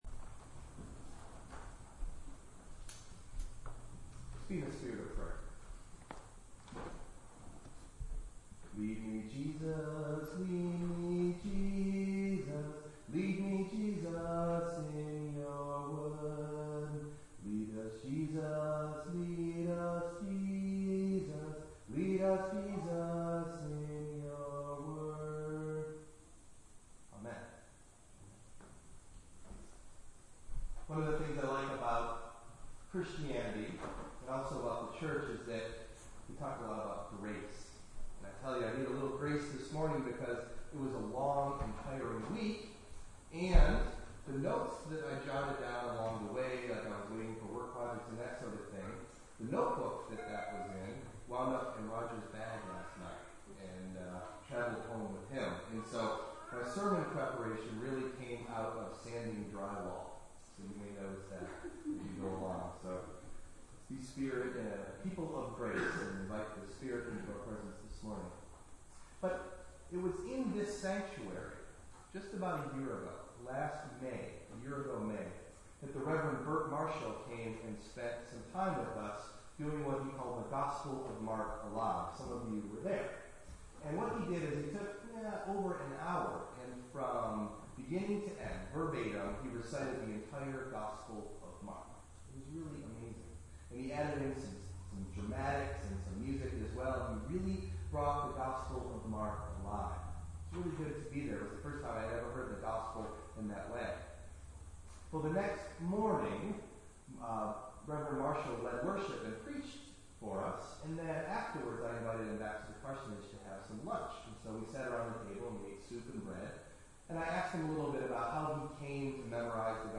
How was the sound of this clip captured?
Delivered at: The United Church of Underhill